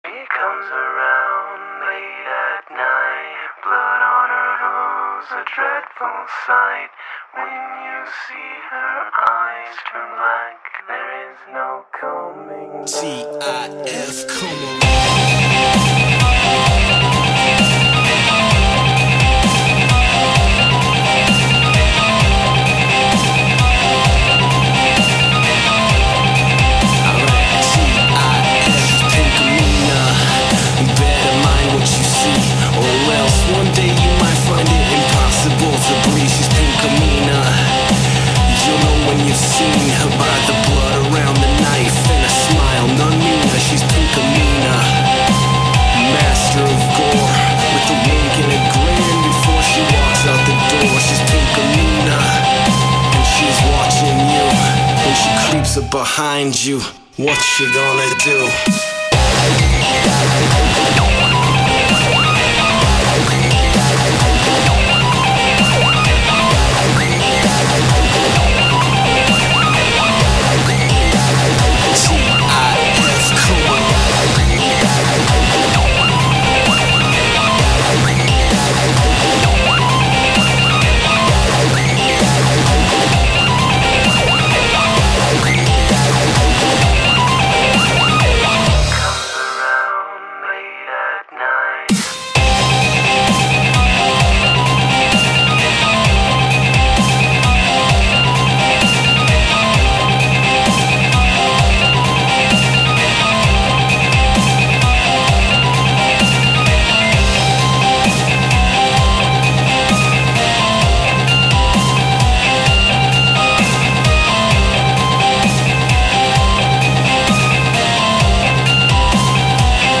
Enjoy another horse song that I did a quick remix of